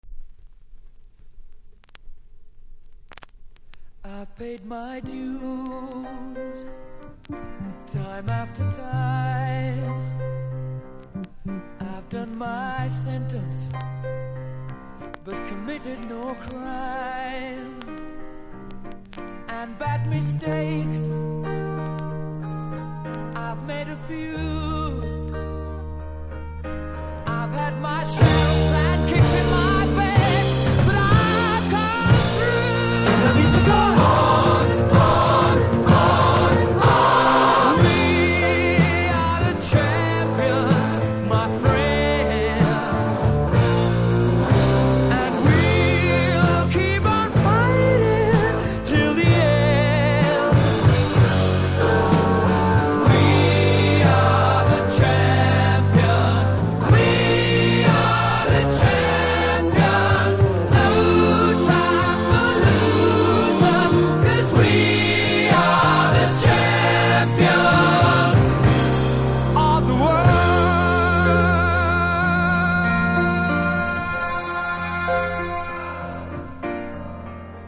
2枚とも同じ場所に序盤ノイズあるのでスタンパーにキズがあったと思われます。